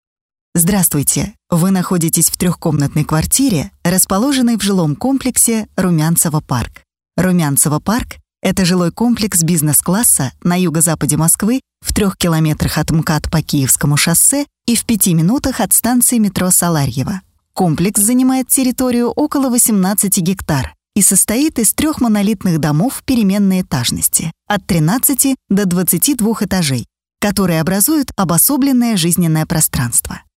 runyЗакадровое озвучивание для жилого комплекса (028)